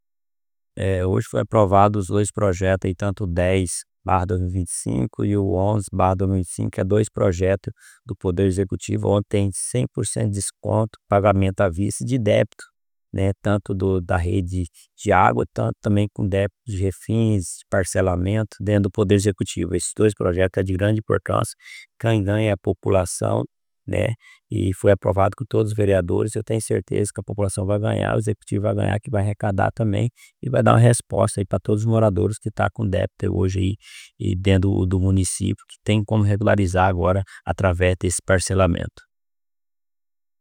O presidente da Câmara Municipal de Juína, o vereador Aélcio Moreira de Oliveira (Neguinho da Borracharia) fala sobre a aprovação dos PLs 10 e 11 que tratam do Refis da Prefeitura e DAES em Juína.